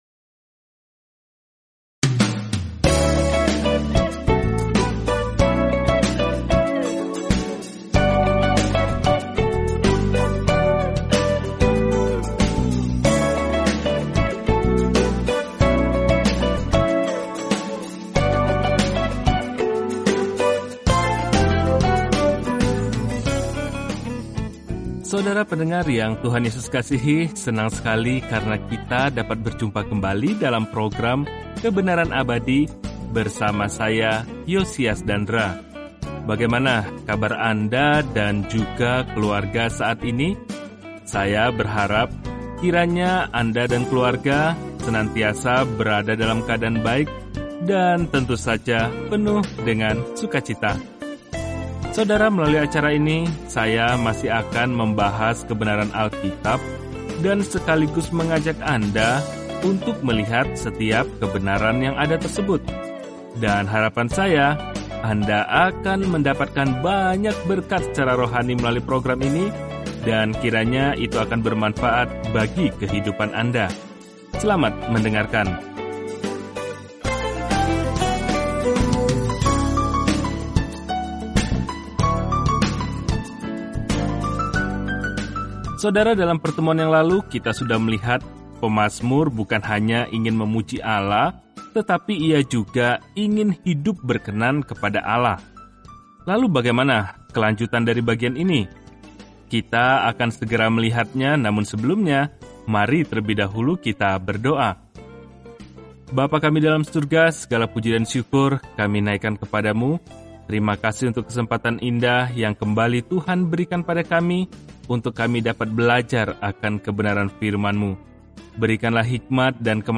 Firman Tuhan, Alkitab Mazmur 52 Mazmur 53 Mazmur 54 Mazmur 55 Hari 28 Mulai Rencana ini Hari 30 Tentang Rencana ini Mazmur memberi kita pemikiran dan perasaan tentang serangkaian pengalaman bersama Tuhan; kemungkinan masing-masing aslinya disetel ke musik. Bacalah Mazmur setiap hari sambil mendengarkan pelajaran audio dan membaca ayat-ayat tertentu dari firman Tuhan.